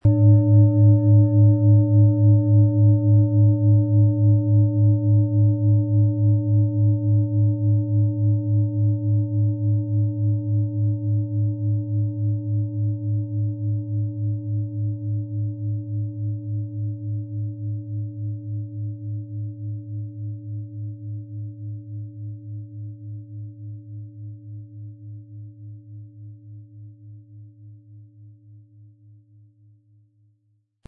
• Tiefster Ton: Neptun
• Höchster Ton: Pluto
Unter dem Artikel-Bild finden Sie den Original-Klang dieser Schale im Audio-Player - Jetzt reinhören.
Lieferung inklusive passendem Klöppel, der gut zur Klangschale passt und diese sehr schön und wohlklingend ertönen lässt.
PlanetentöneSaturn & Neptun & Pluto (Höchster Ton)
MaterialBronze